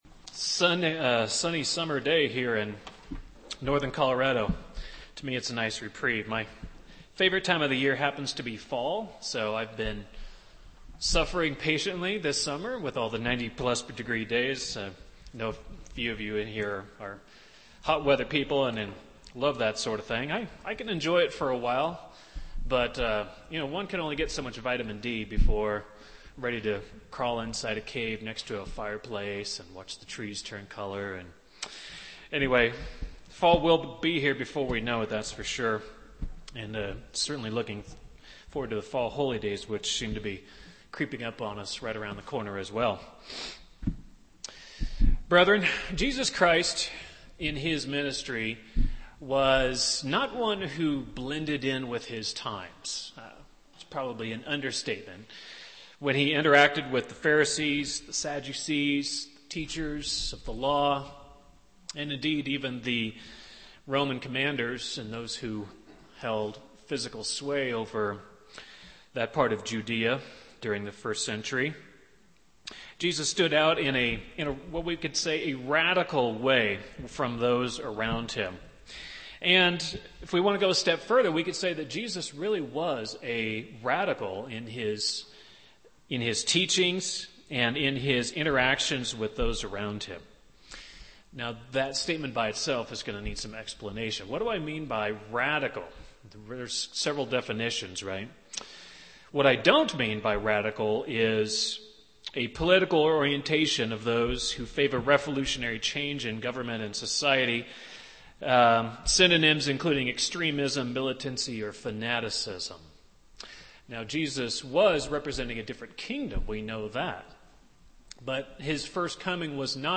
Sermons
Given in Loveland, CO